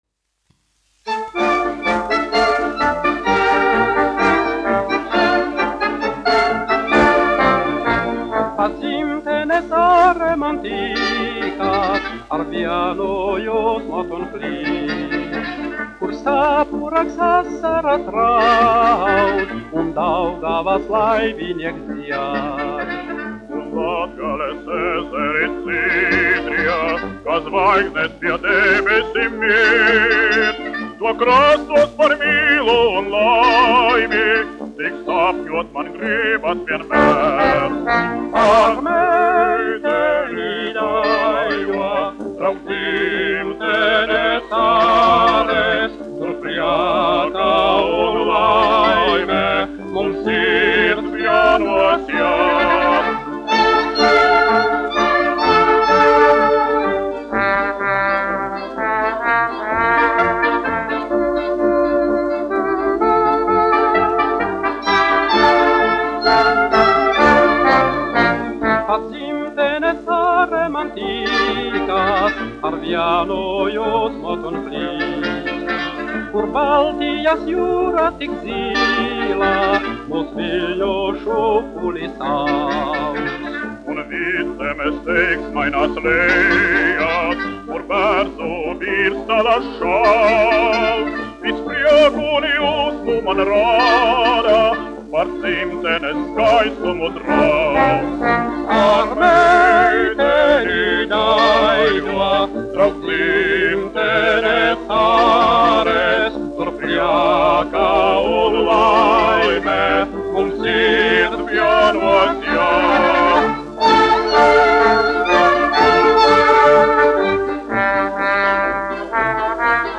1 skpl. : analogs, 78 apgr/min, mono ; 25 cm
Fokstroti
Populārā mūzika -- Latvija
Skaņuplate